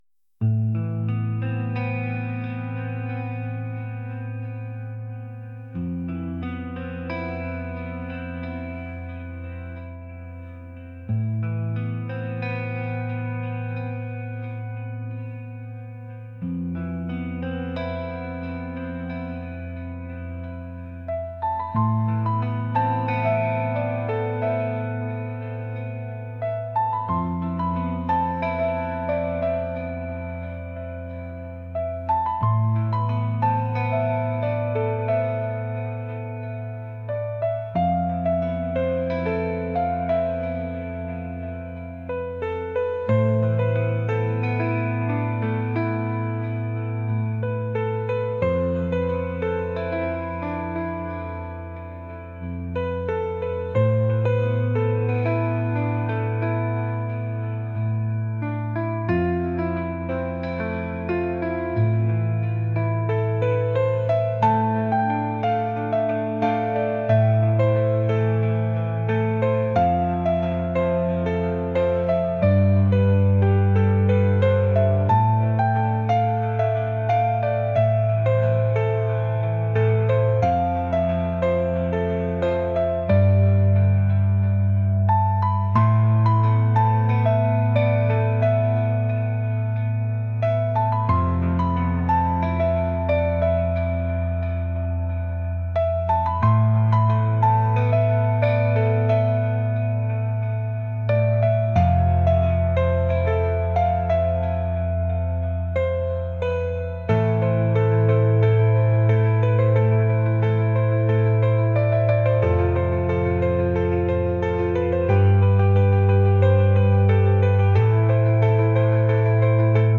rock | ambient | electronic